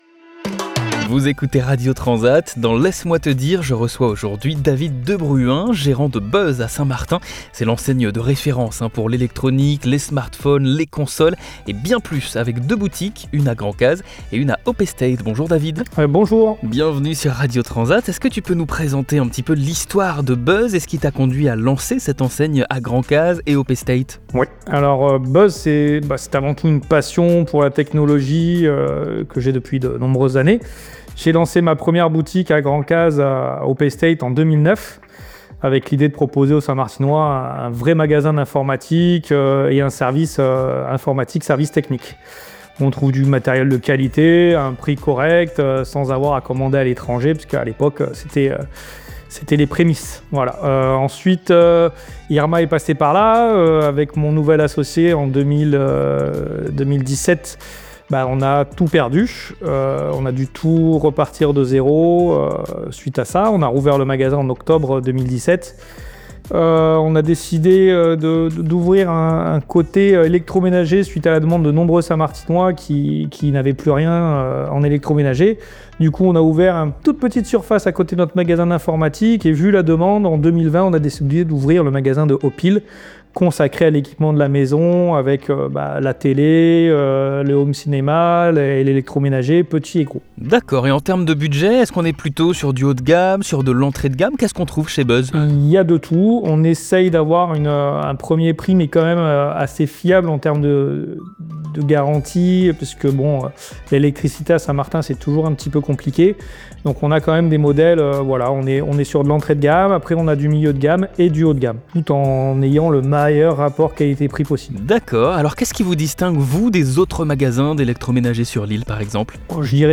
22 décembre 2025 Écouter le podcast Télécharger le podcast Dans cette interview